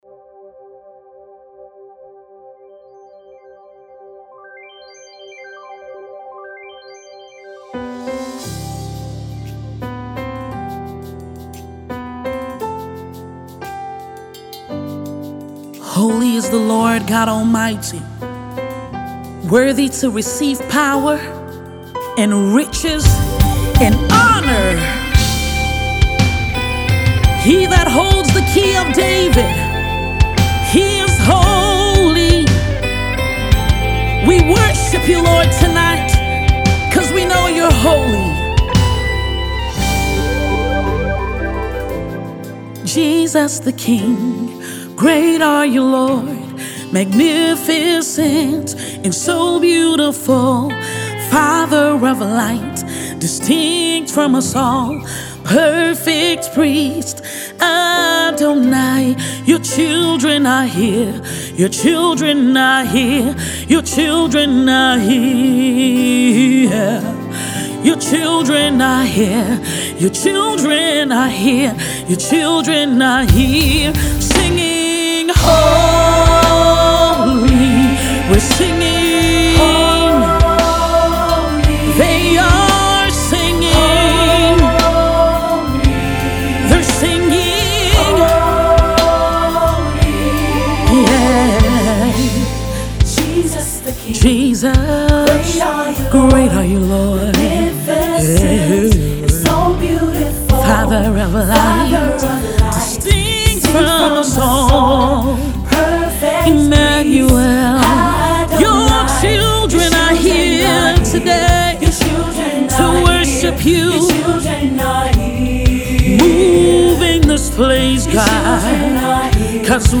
Tags:  Gospel Music,  Naija Music,   Lyrics Video